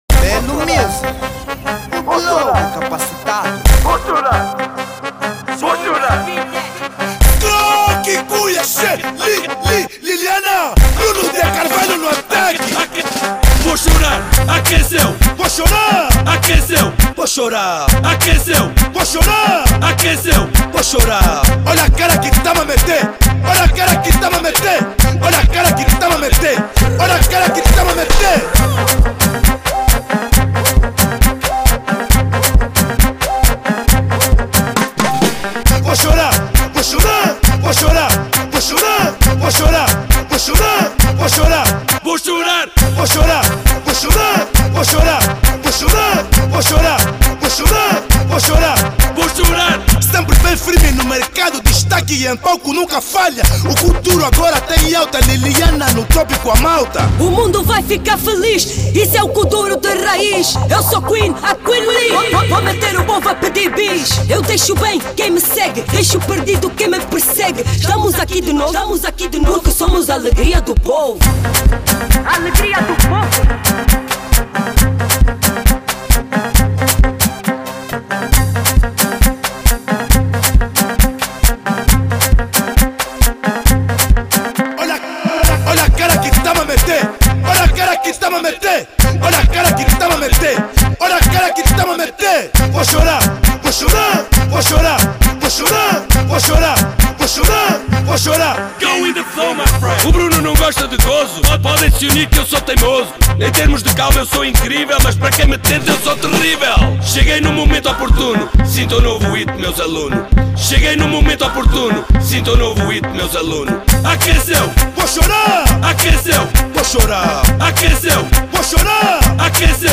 Género: Afro House